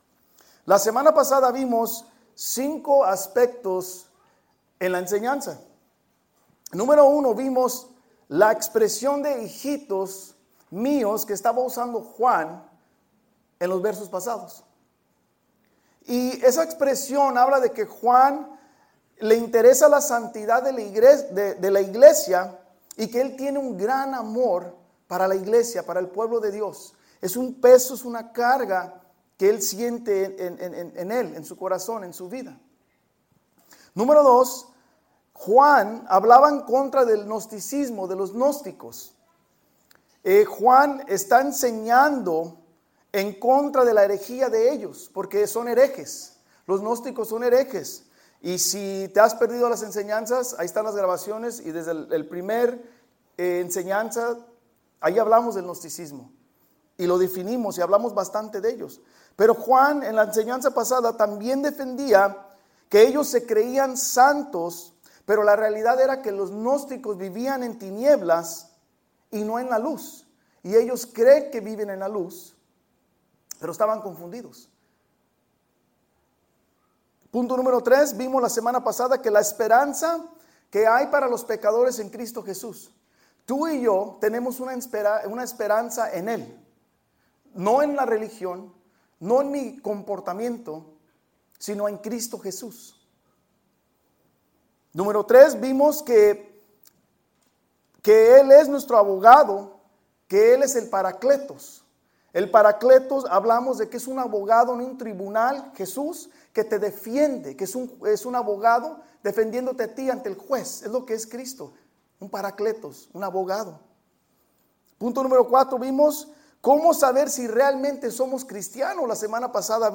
Estudio bíblico verso por verso para entender la palabra de Dios.